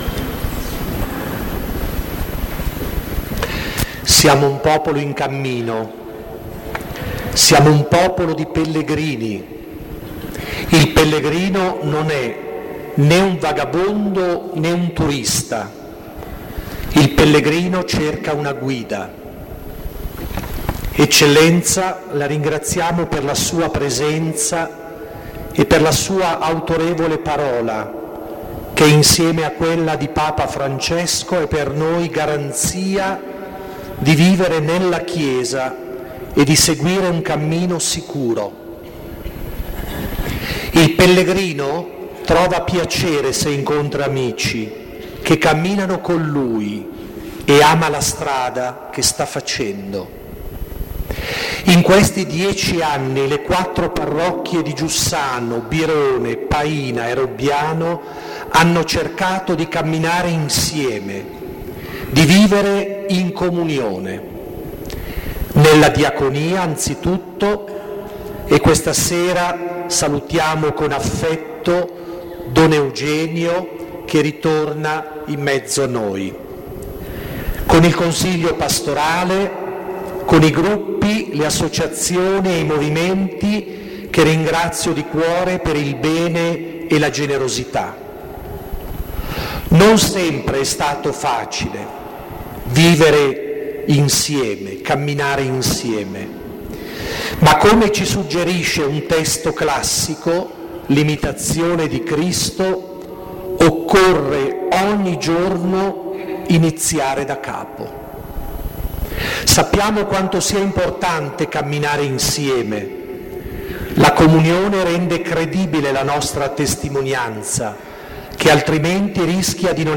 in occasione del 10° anniversario della costituzione ufficiale
Basilica SS. Filippo e Giacomo – ore 21.00